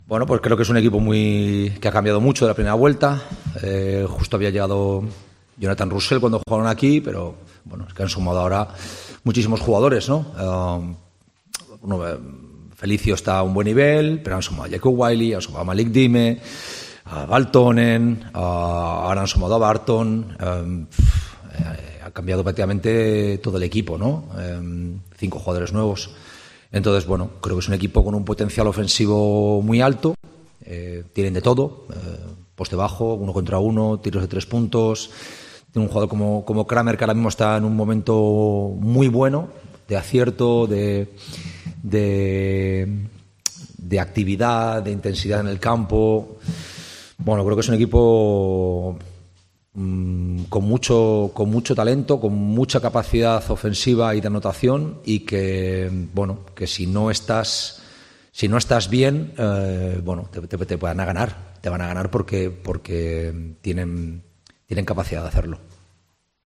Durante su intervención en la sala de prensa del Carpena